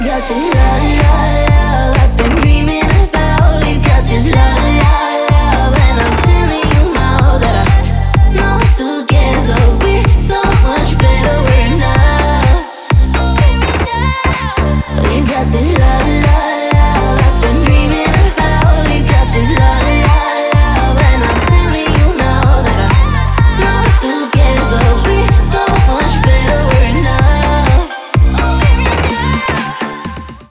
зажигательные
женский голос
Electronic
EDM
клубняк
Стиль: house